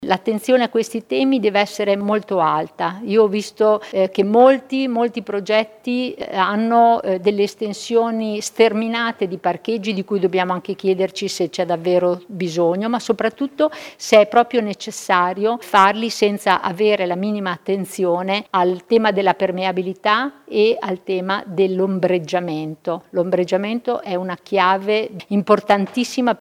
Meno cemento e più attenzione agli spazi verdi. Questa la visione della città dell’assessore all’urbanistica Carla ferrari…